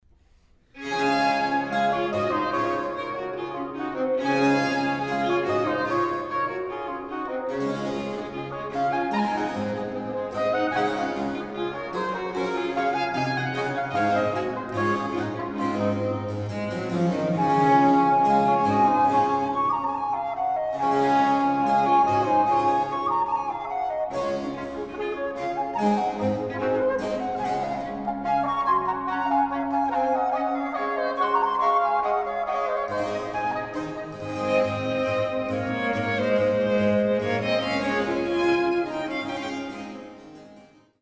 Quintett Op.22 Nr.1 D-Dur für Traversflöte, Oboe, Violine, Violoncello
Satz Allegro (rechte Maustaste)